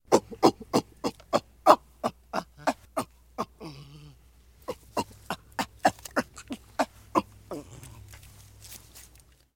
Monkey sound - Eğitim Materyalleri - Slaytyerim Slaytlar
monkey-sound